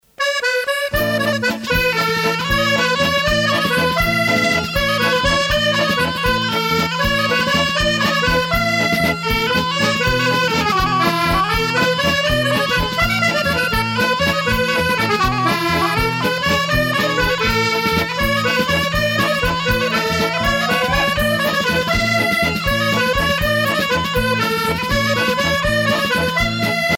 danse : bourree